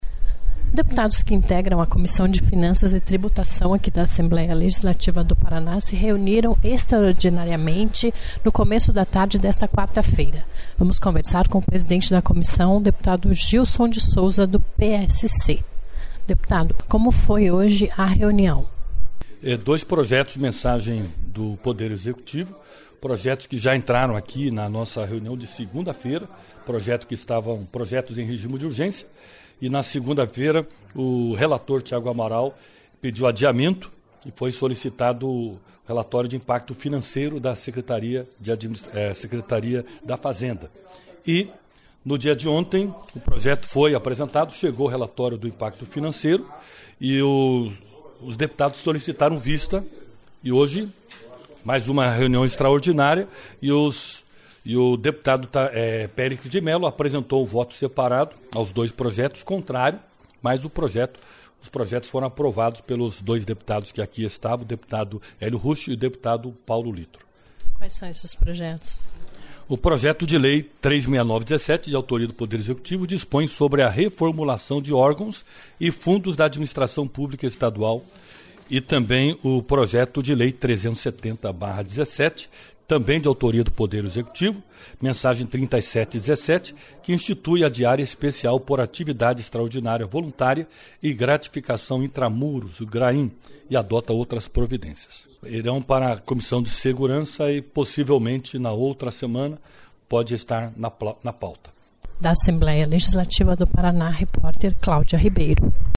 Confira a íntegra da entrevista  com deputado Gílson de Souza (PSC), rpesidente da Comissão.